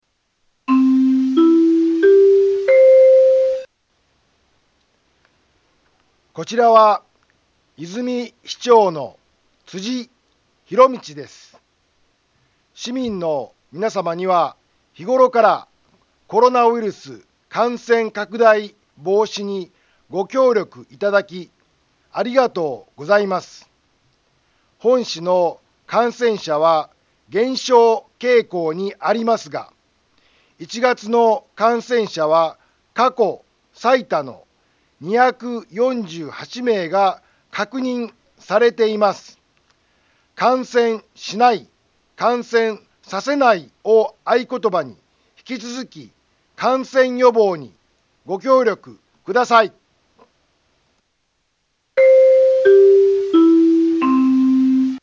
Back Home 災害情報 音声放送 再生 災害情報 カテゴリ：通常放送 住所：大阪府和泉市府中町２丁目７−５ インフォメーション：こちらは、和泉市長の辻 ひろみちです。